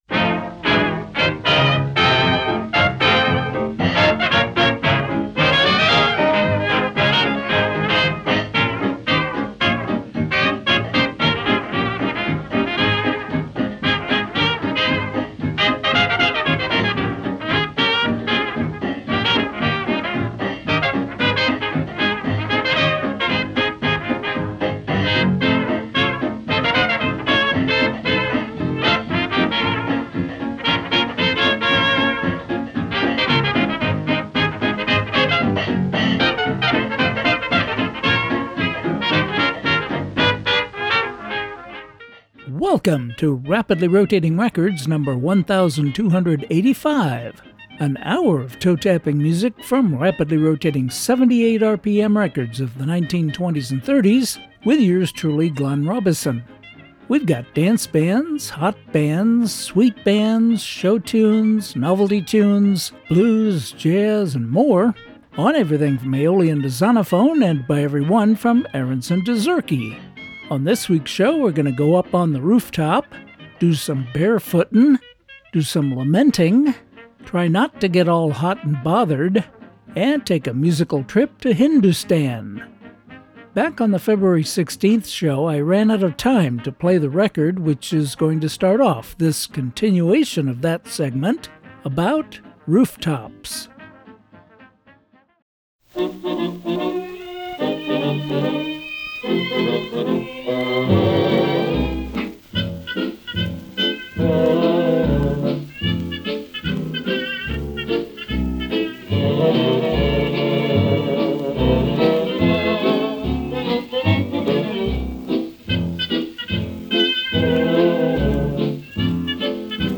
Well, because in the fourth segment of the show, we’re going to take a musical journey to Hindoostan. You’ll learn a little about the word and hear three nice vintage “Hindoo” songs. In other segments, we’ll go up on the rooftop, go barefoot, try to keep from getting all hot and bothered and do some lamenting.